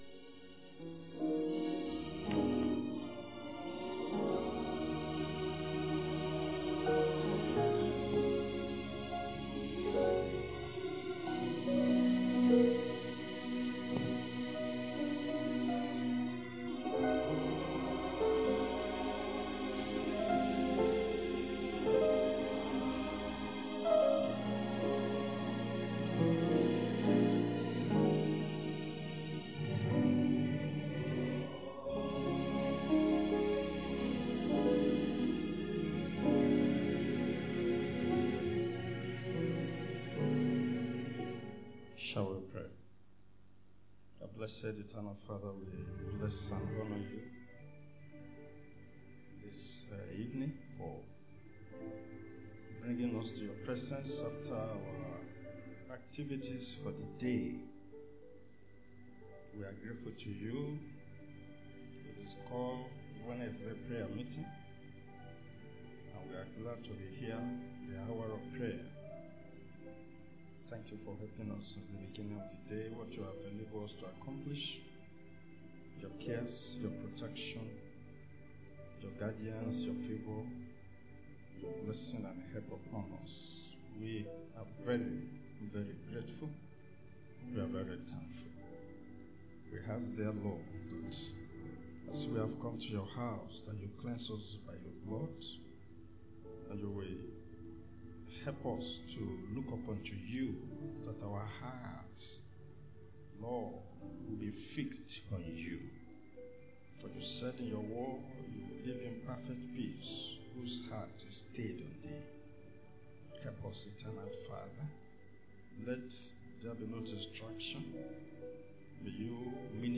Wed. Prayer Meeting